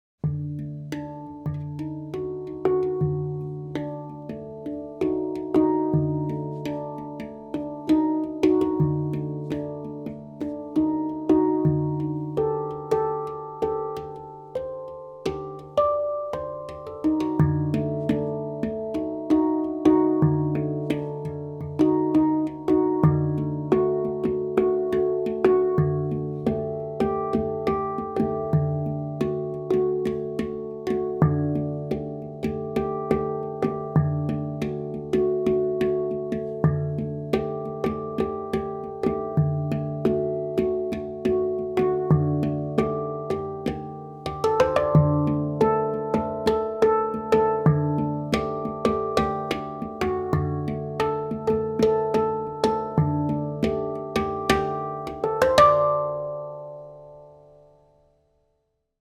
Instrumentet er laget av rustfritt stål, noe som gir en klar tone, lang sustain og balanserte overtoner.
• Stemning: D-Hijaz – eksotisk, orientalsk og emosjonell klang.
• Lang sustain med balanserte overtoner.
D3, A3, D4, D#4, F#4, G4, A4, C5, D5
Hijaz gir en eksotisk og orientalsk klang som skiller seg tydelig fra moll og dur.